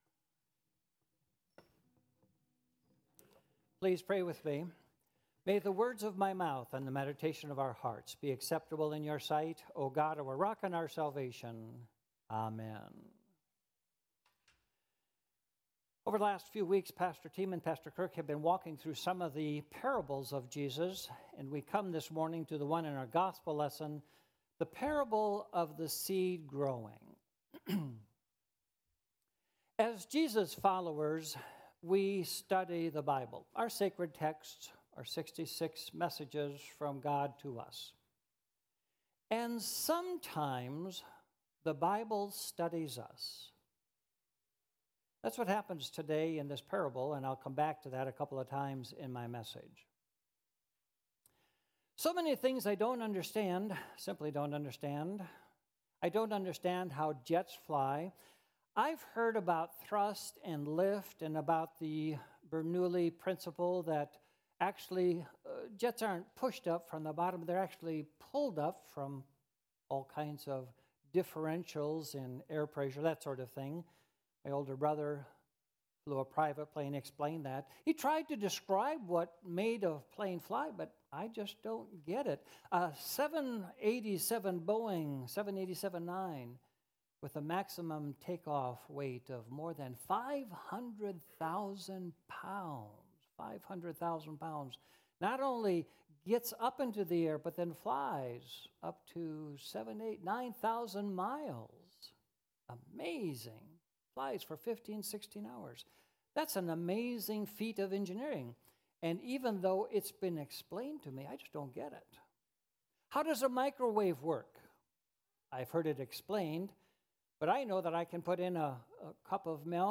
Sermons by Immanuel Crystal Lake